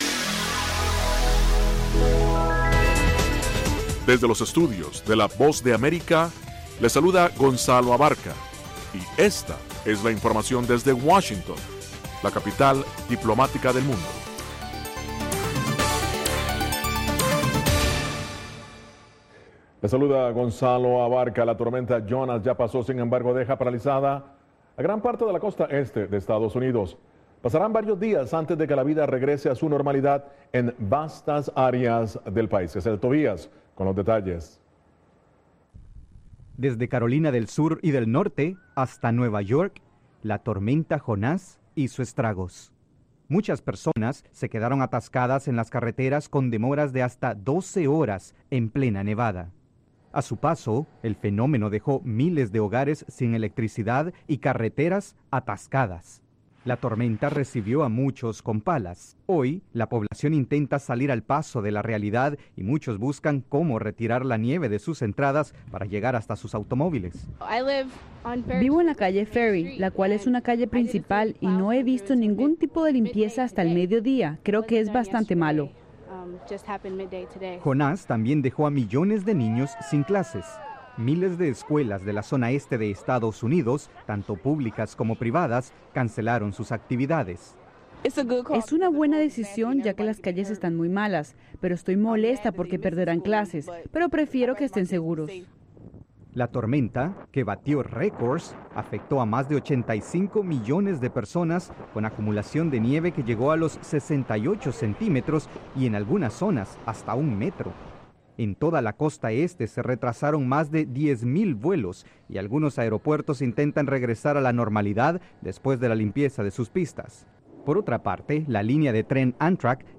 Diez minutos de las noticias más relevantes del día, ocurridas en Estados Unidos y el resto del mundo.